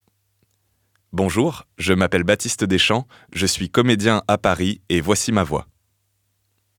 Présentation voix
20 - 45 ans - Baryton Ténor